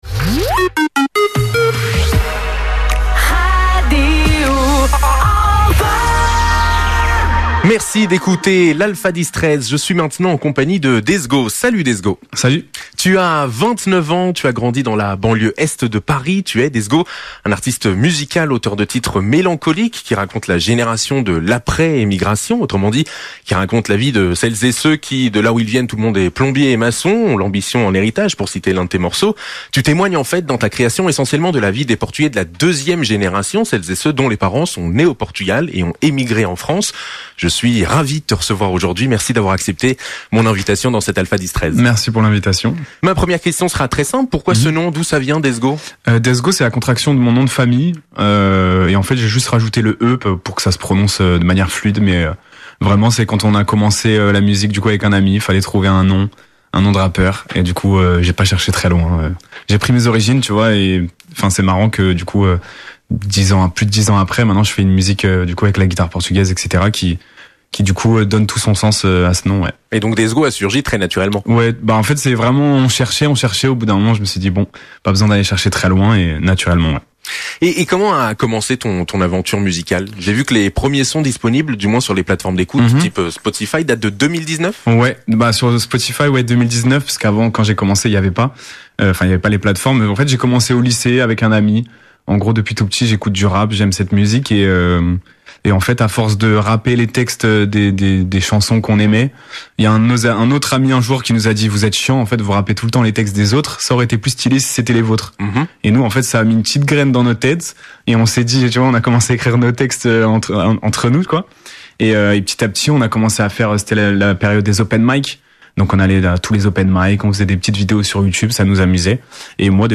Entretien
Dans les studios de Radio Alfa